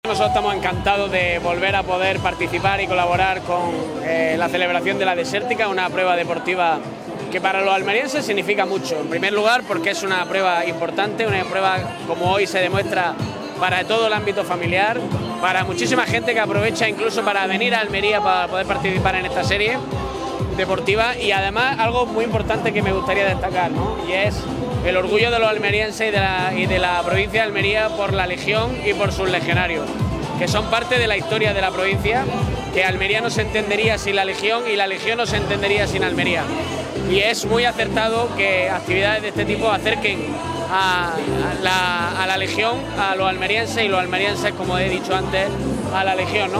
Por su parte, el diputado de Promoción Agroalimentaria, Carlos Sánchez, ha puesto de relieve la importancia para la provincia de Almería de la celebración de un evento como La Desértica y “el gran preludio que supone la Minidesértica con la feria del corredor en la que participan empresas de la marca gourmet ‘Sabores Almería’.